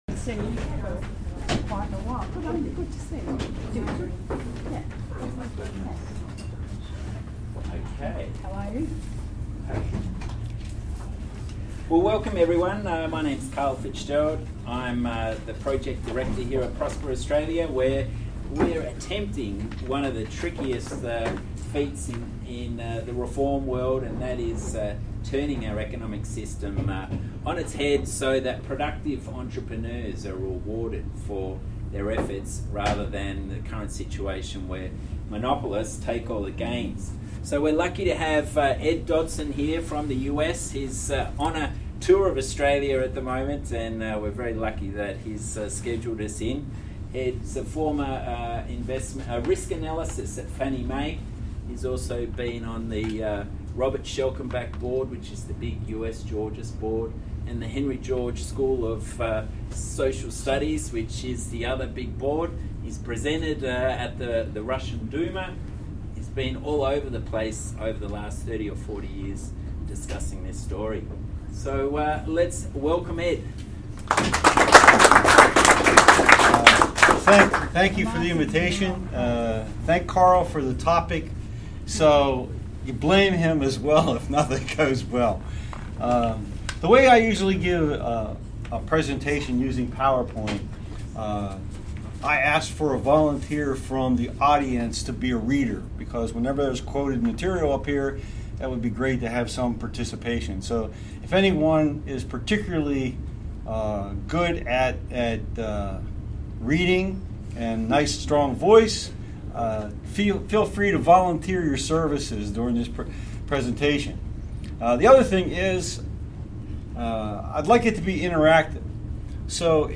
Geo-Libertarianism: Across the Left-Right Divide The audio of a presentation made at the offices of Prosper Australia, April, 2018